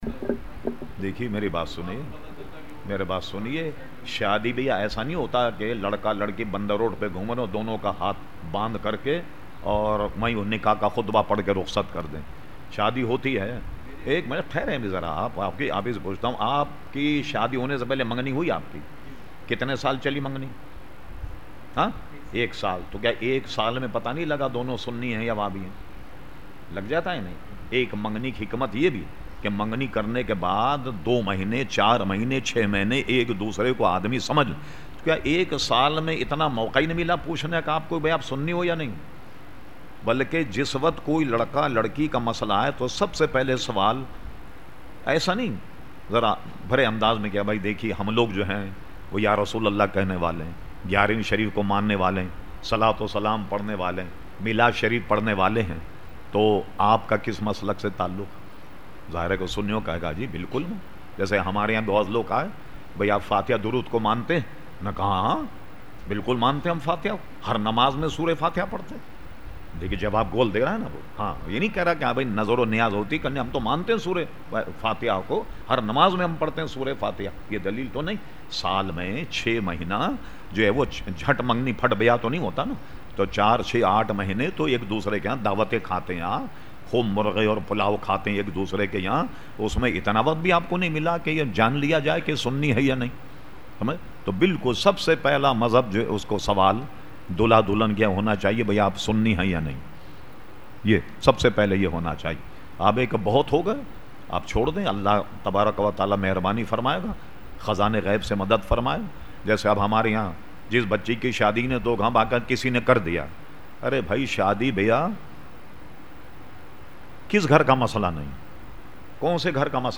Q/A Program held on Sunday 26 September 2010 at Masjid Habib Karachi.
Hazrat Allama Maulana Syed Shah Turab ul Haq Qadri (Q&A)